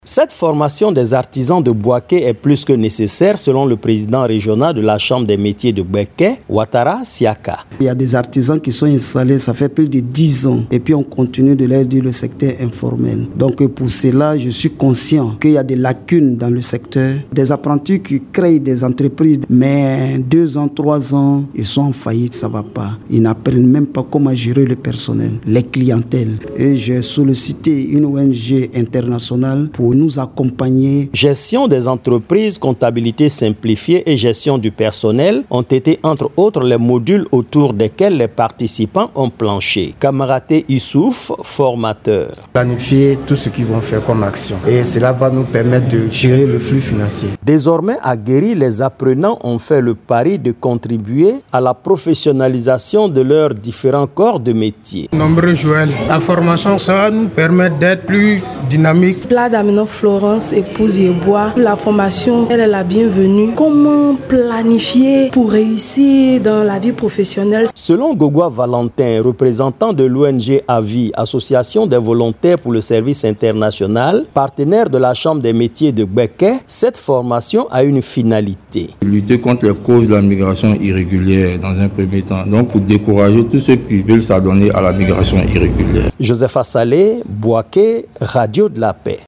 Le compte-rendu